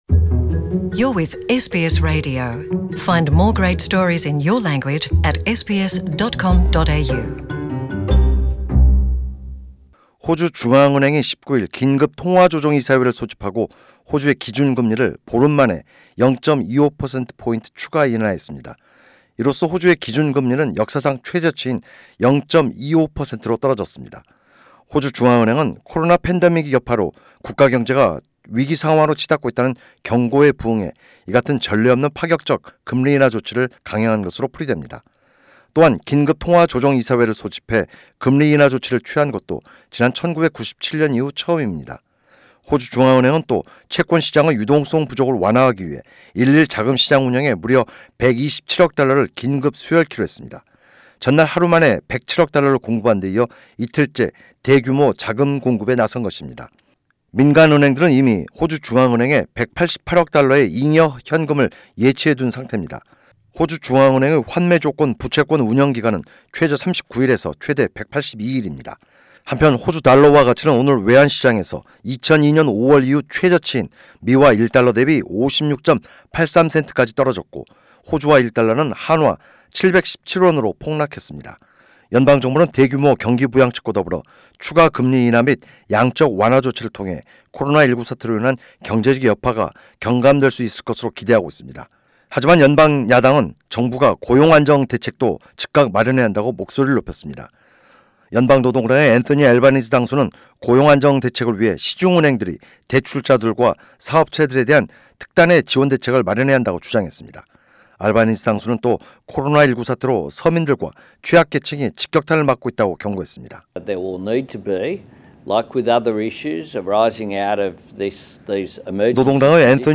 [본 기사 내용은 팟캐스트를 통해 오디오 뉴스로 들으실 수 있습니다.]